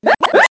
One of Koopa Troopa's voice clips in Mario Kart Wii